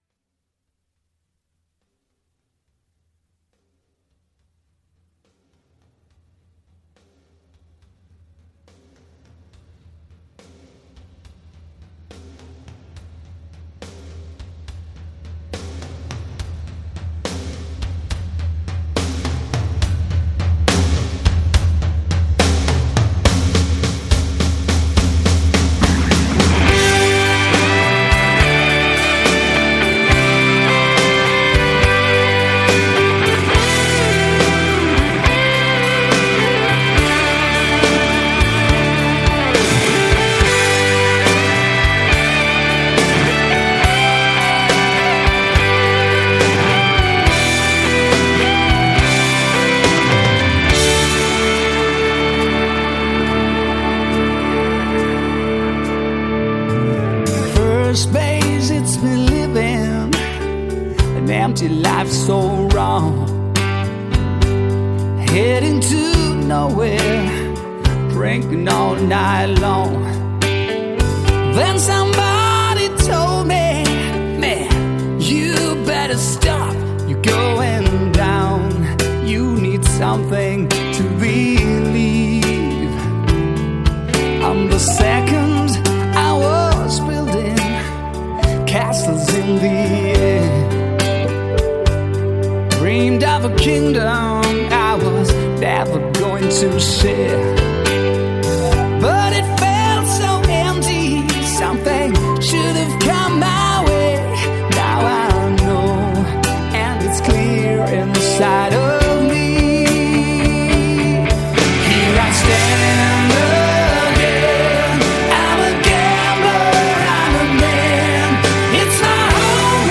Жанр: Melodic Hard Rock